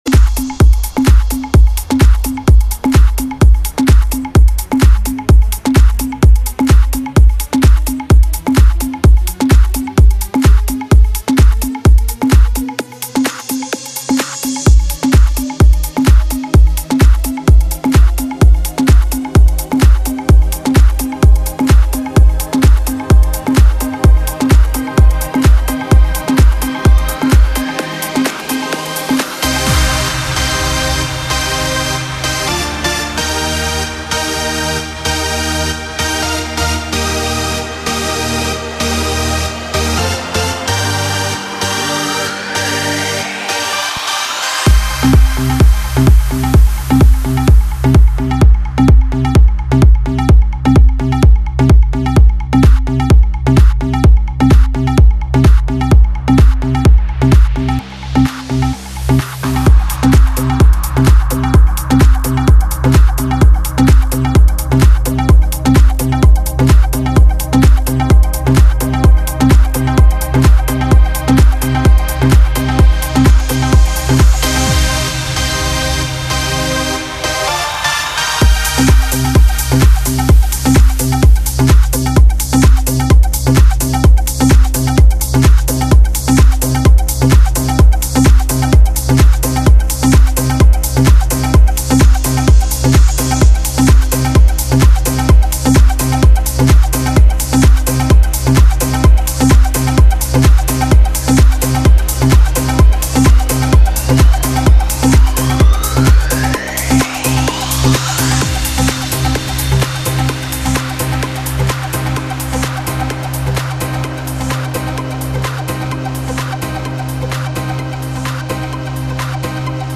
Невероятно красивый вокальный хаус!
Стиль: Progressive House / Club House / Vocal House
Позитив | Энергия | Чувство | Ритм | Стиль | Движение